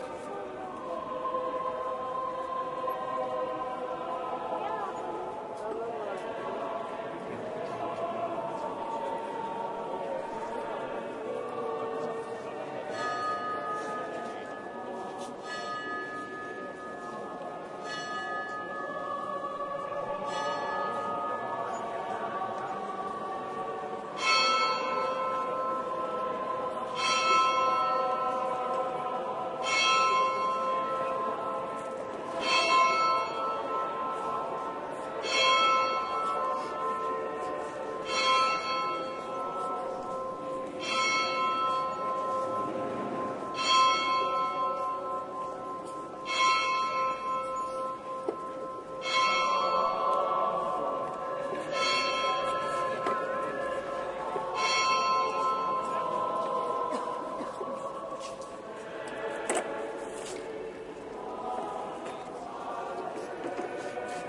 描述：我在晚上12o'clock的girona教堂附近，听起来是这样的。背景有一些嘶嘶声
Tag: 教堂 现场录制